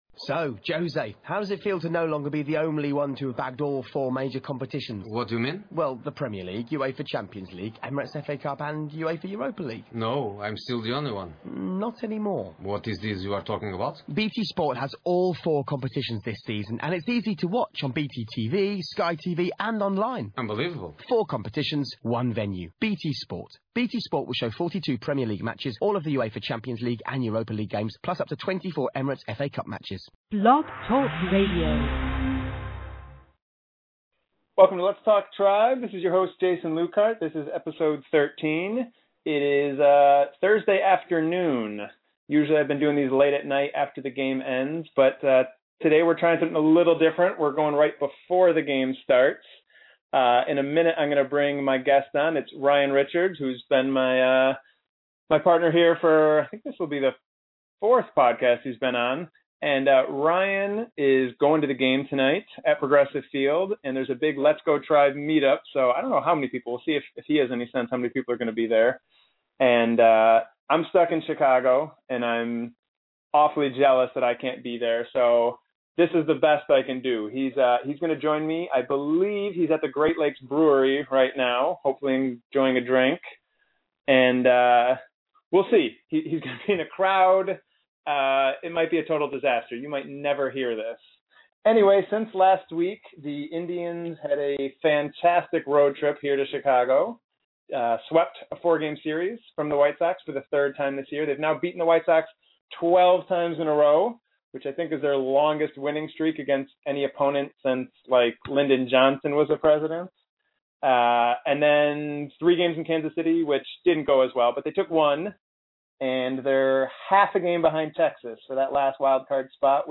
joins the show from the Great Lakes brewery in downtown Cleveland